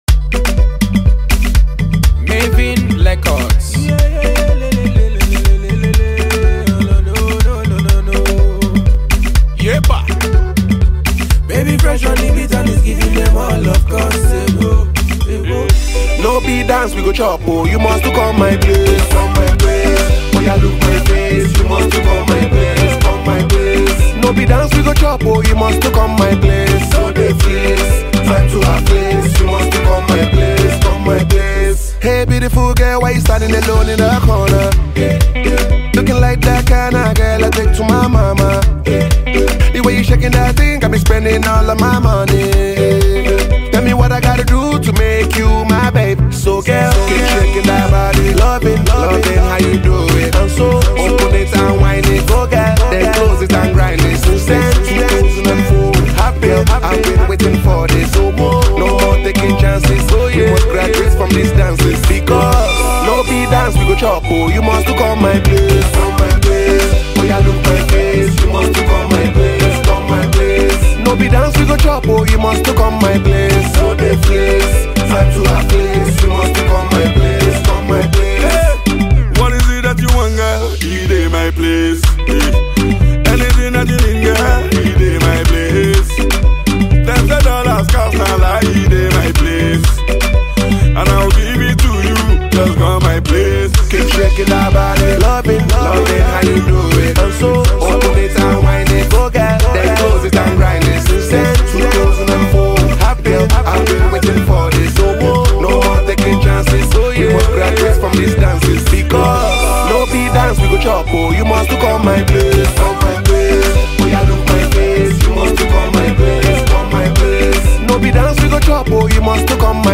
new single
Download this nerve boosting hit.